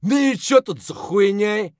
scav2_mutter_27_bl.wav